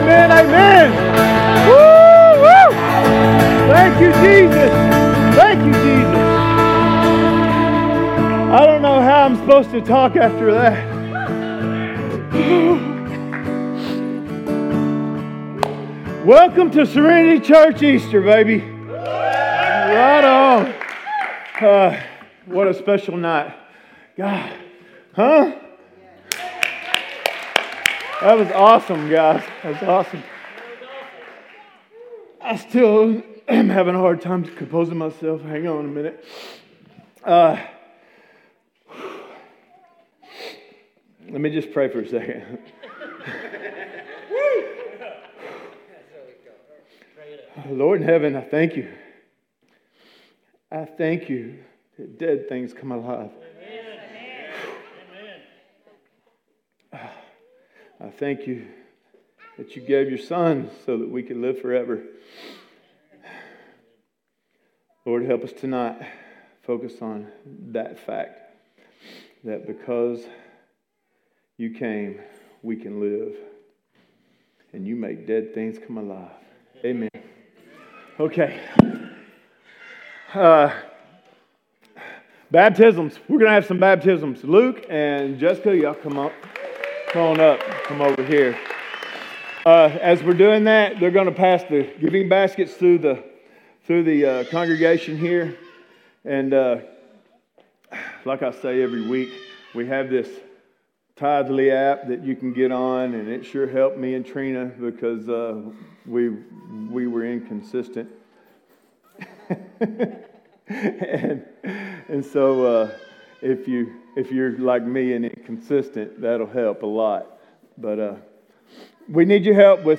Sermons | Serenity Church
Easter Service 2026